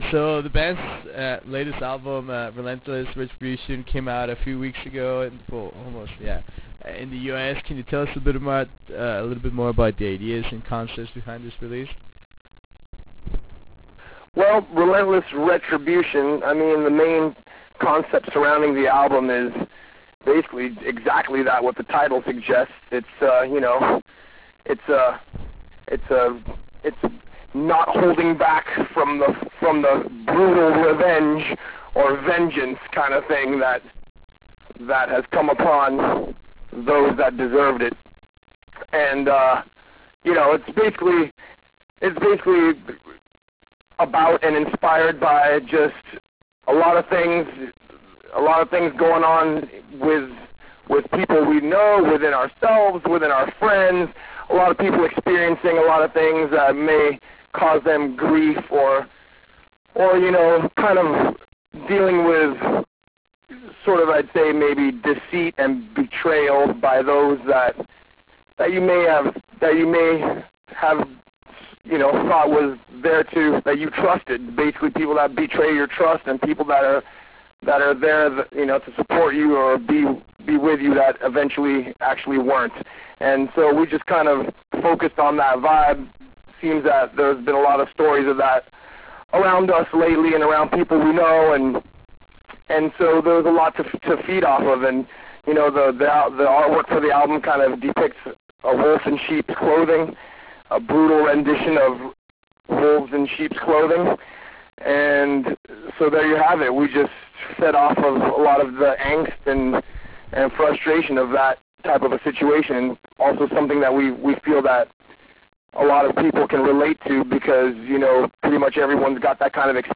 Interview with Death Angel